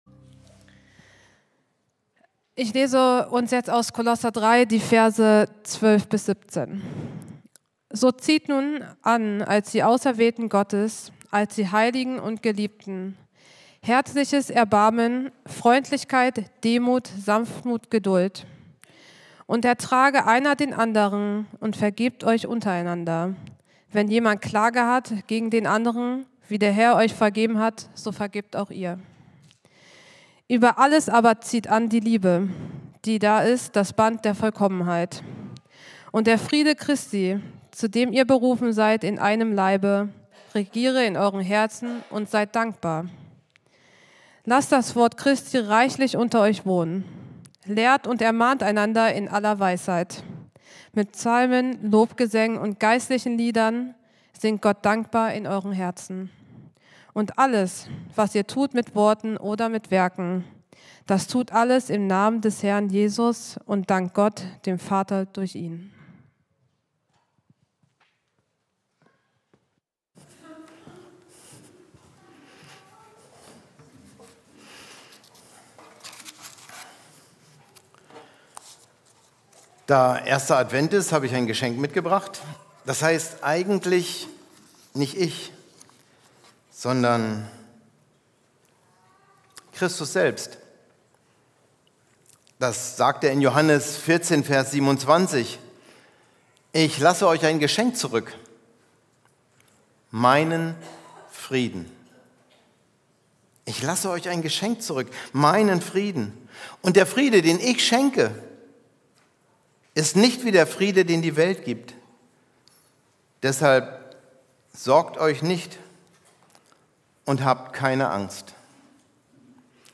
Predigt mp3